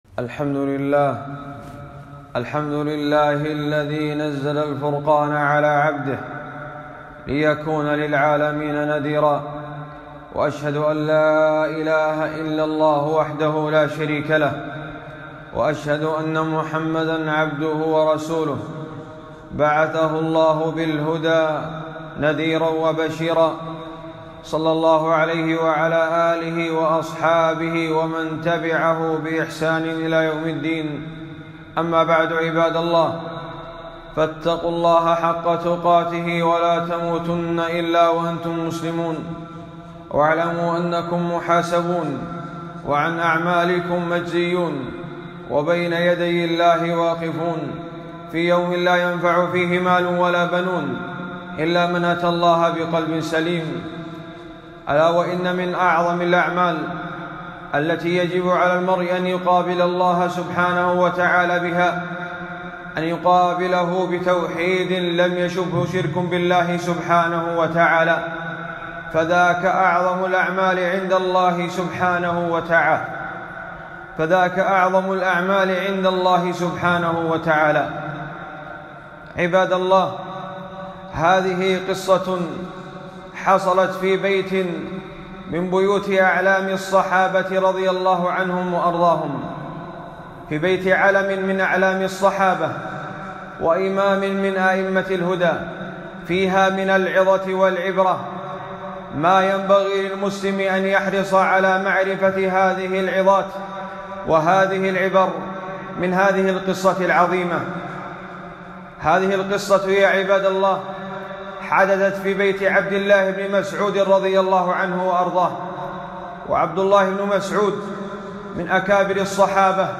خطبة - وقفات مع قول ابن مسعودإن آل عبد الله لأغنياء عن الشرك